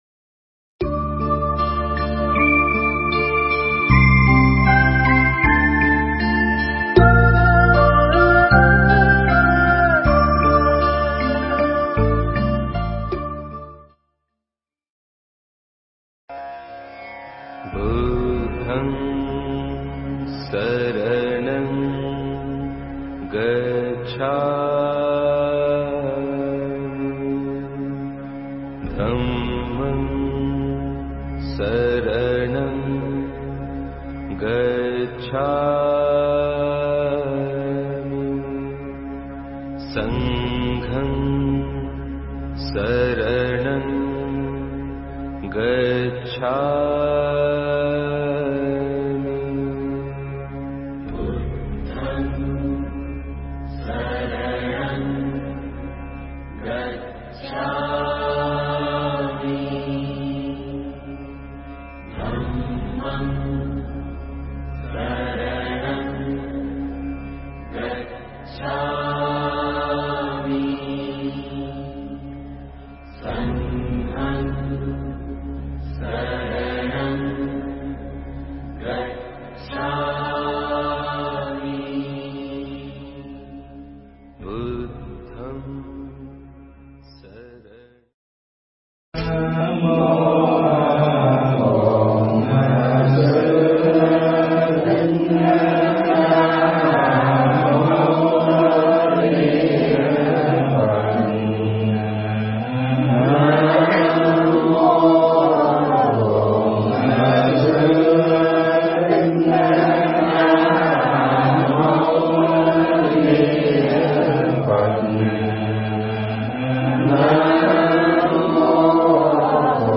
Nghe Mp3 thuyết pháp Kinh Trung Bộ